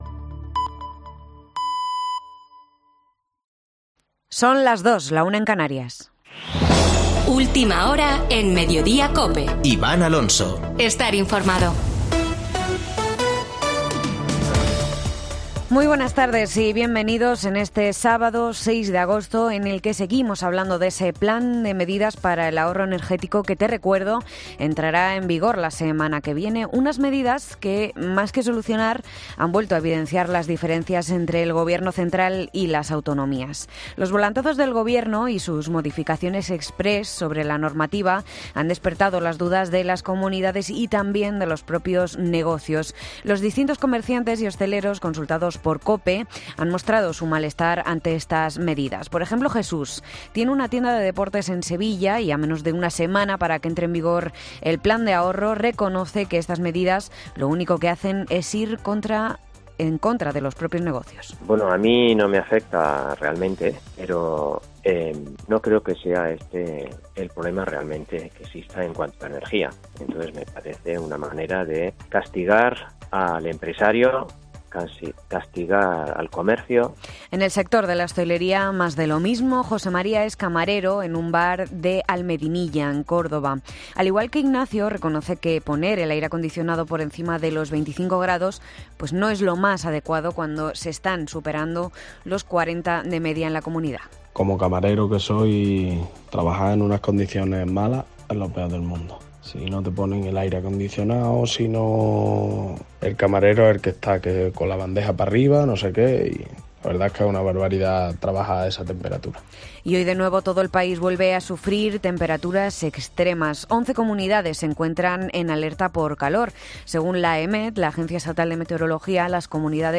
Boletín de noticias de COPE del 6 de agosto de 2022 a las 14.00 horas